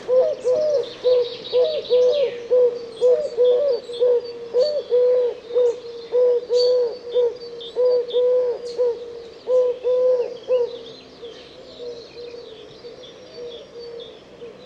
Son chant est caractérisé par une structure rythmique en trois notes, la deuxième étant accentuée.
tourterelleTurque.mp3